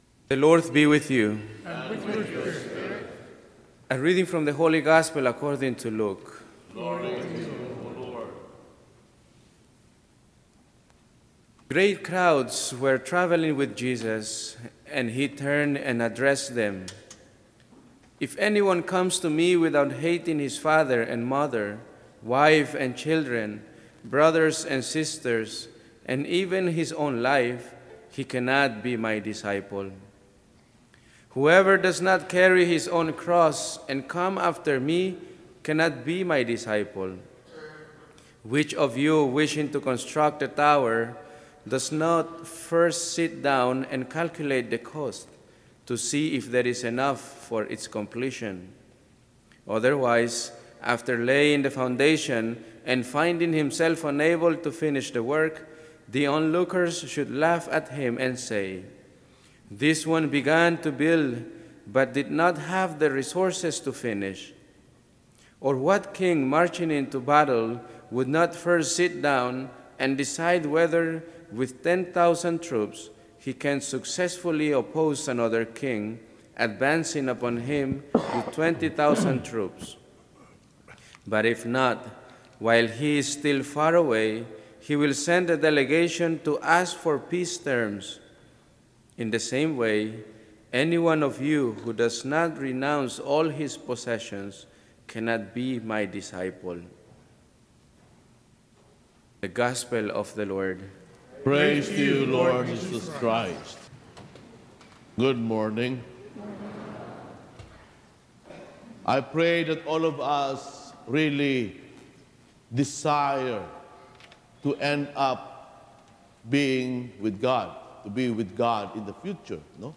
Homily for Sunday September 08, 2019